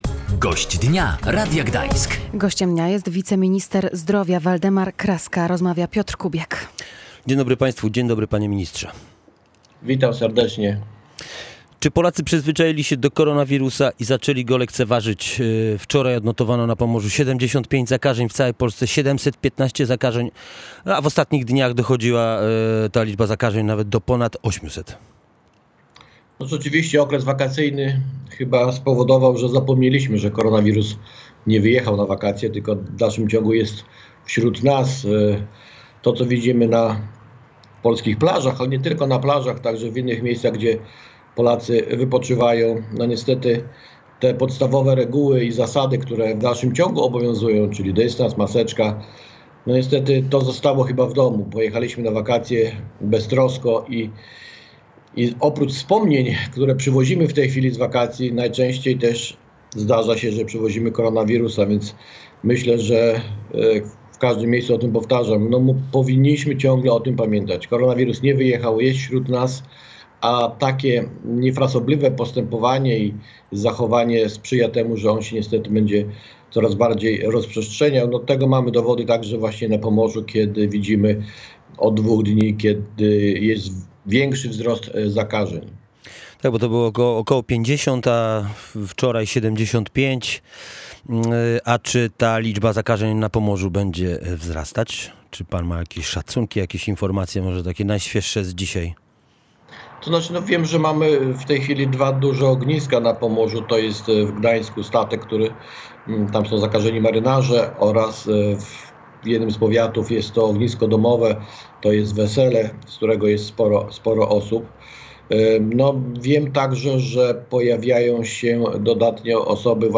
Te osoby, które są na oddziałach intensywnej opieki medycznej, przechodzą to w sposób dość dramatyczny – zauważył gość Radia Gdańsk.